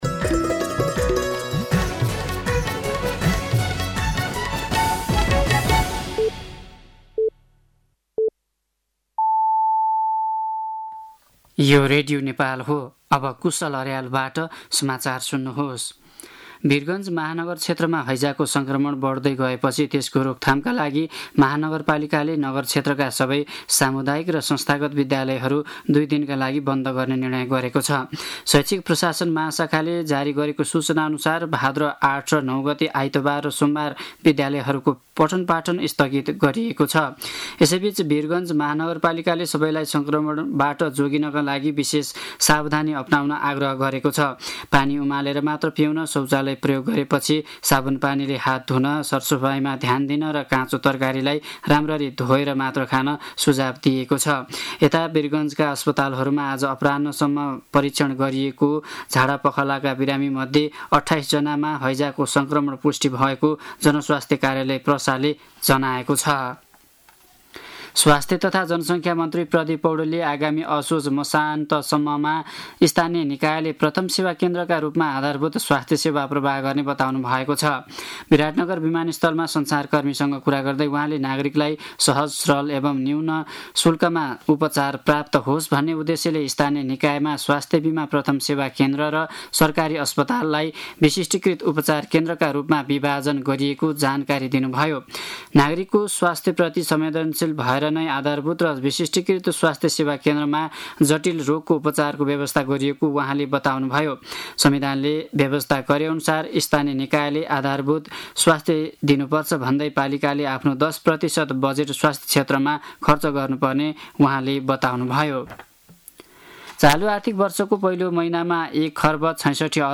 साँझ ५ बजेको नेपाली समाचार : ७ भदौ , २०८२
5.-pm-nepali-news-1-8.mp3